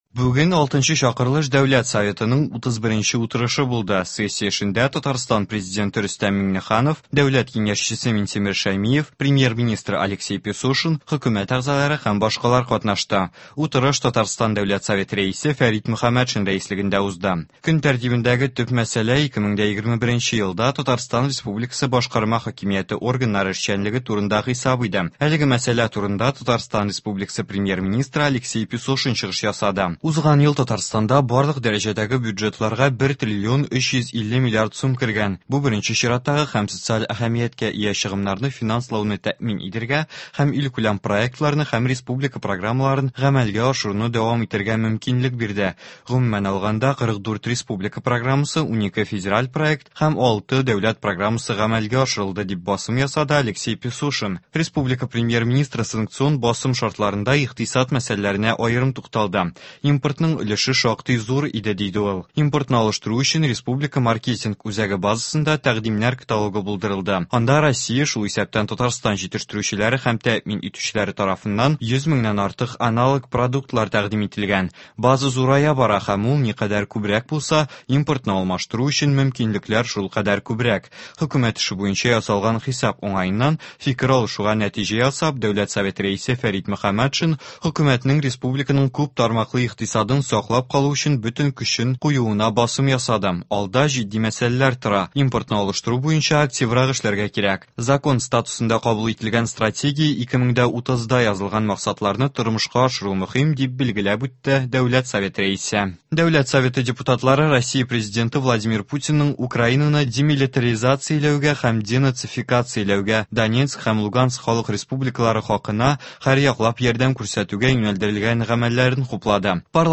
Радиоотчет (21.04.22)